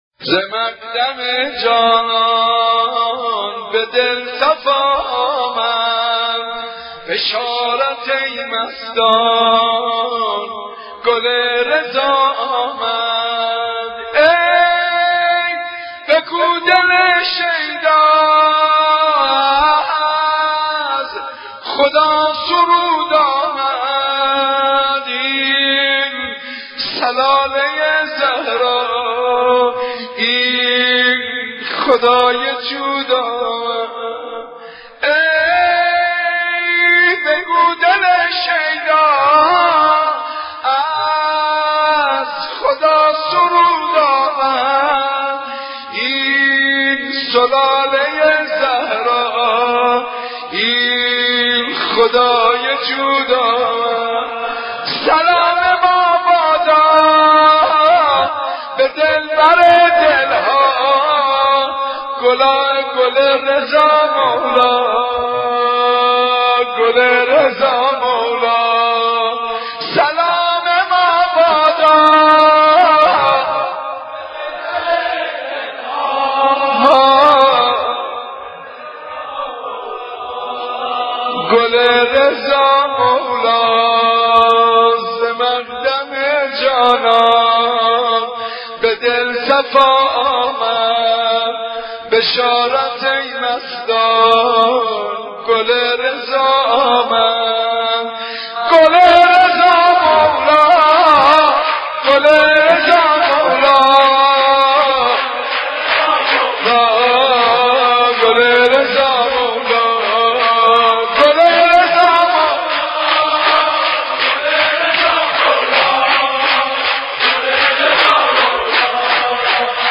مولودی امام جواد
حسینیه صنف لباسفروشها